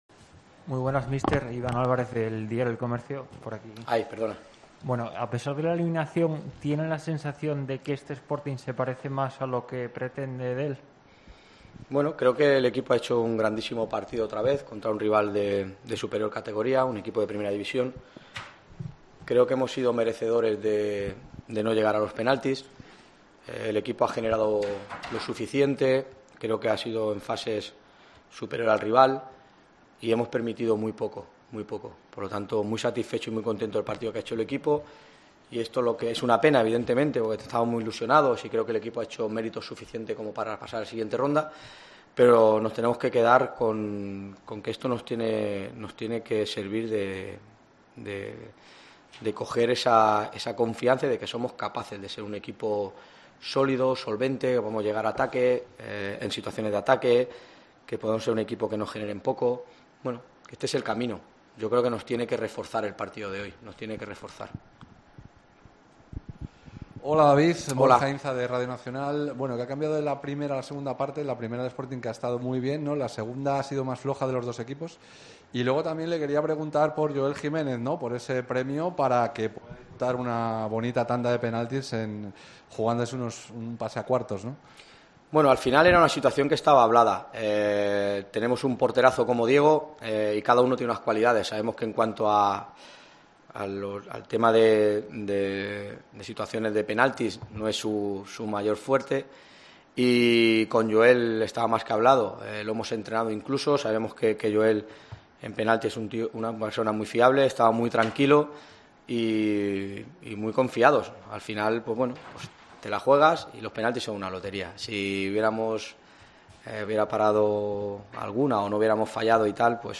Rueda de prenda